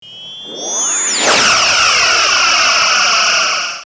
OtherPhaser3.wav